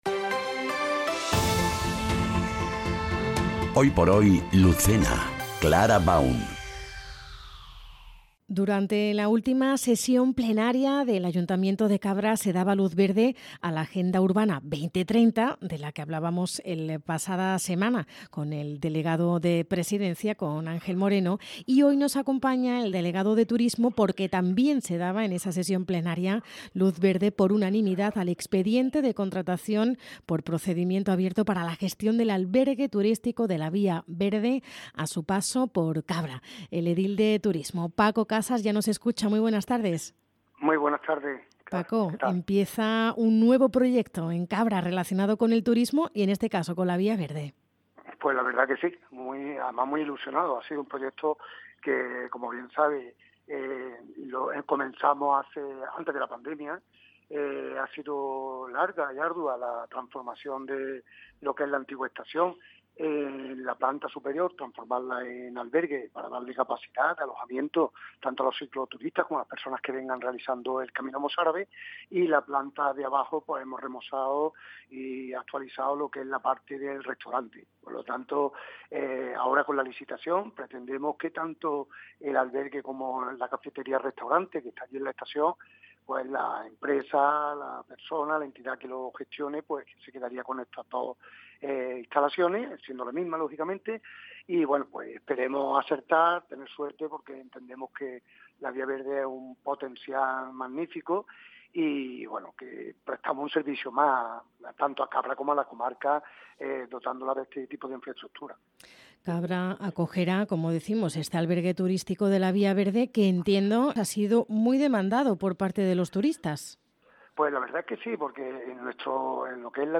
Entrevista | Paco Casas - Albergue Turístico Vía Verde Cabra